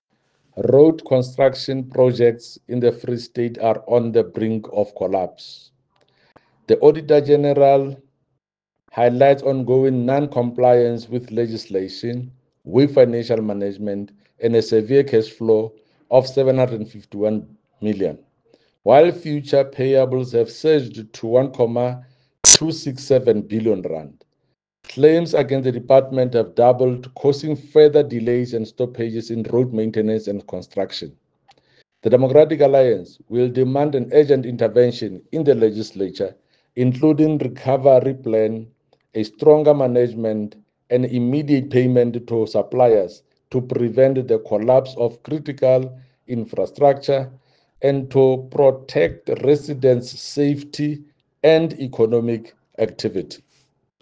Sesotho soundbites by Jafta Mokoena MPL and